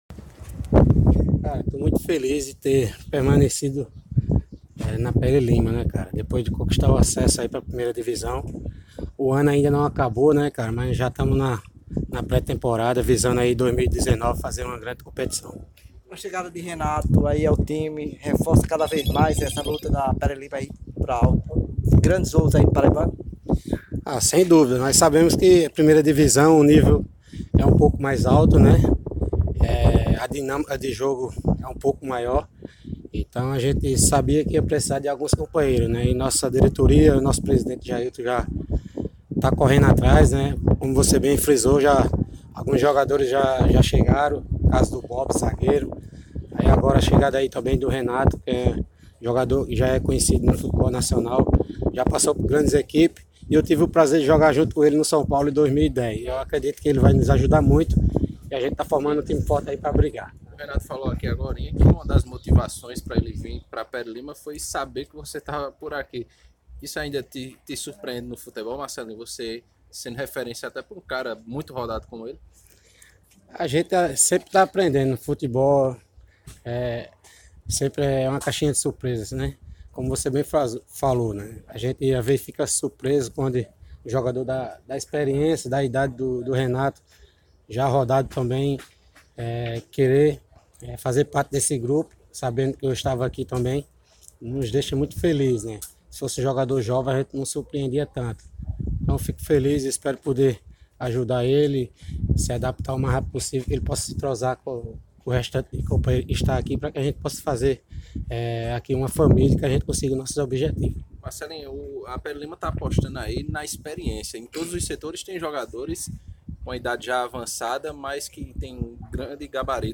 Ouça o depoimento do experiente Marcelinho Paraíba…